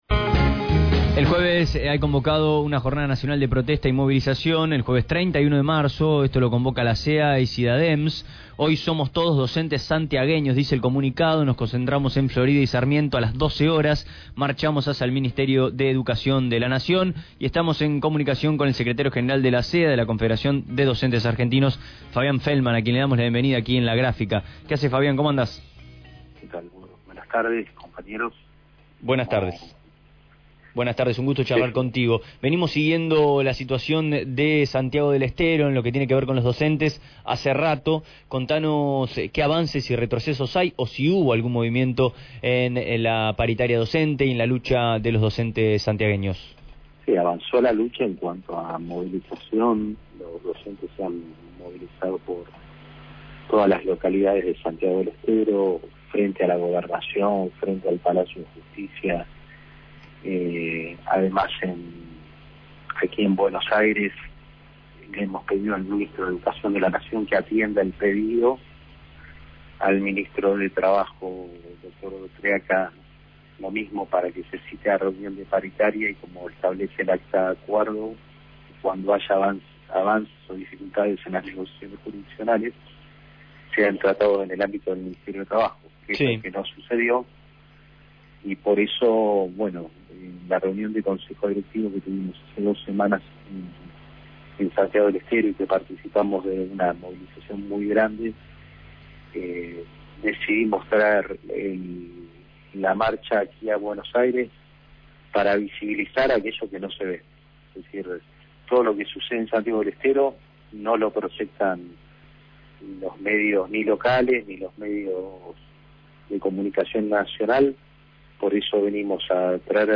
La Confederación de Educadores Argentinos (CEA) y la CGT convocaron a una jornada paro y movilización nacional para el jueves 31 de marzo al no recibir respuesta alguna por parte de las autoridades del Ministerio de Educación Nacional por la crisis educativa que se vive en la provincia de Santiago del Estero, donde el salario básico docente es de 2980 pesos. En comunicación telefónica con Radio Gráfica